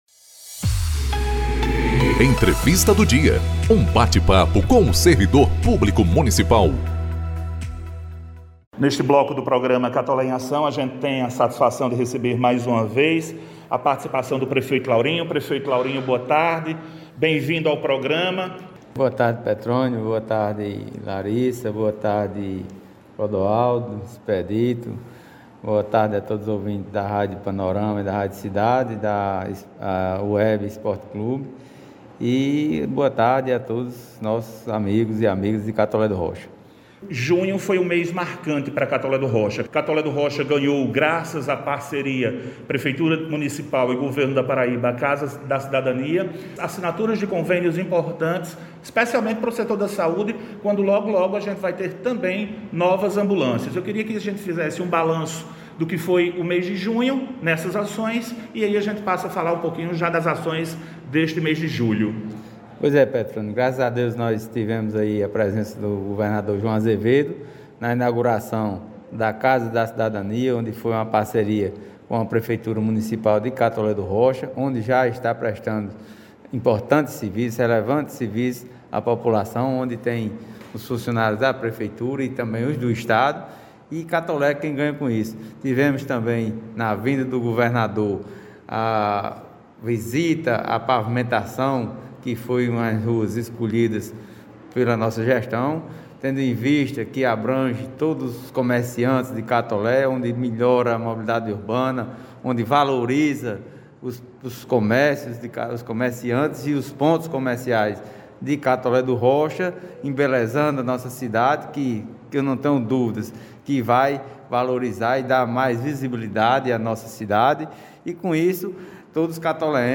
O Programa Institucional “Catolé em Ação” – edição n° 52 – exibiu na sexta-feira (15/07), entrevista com o prefeito Laurinho Maia.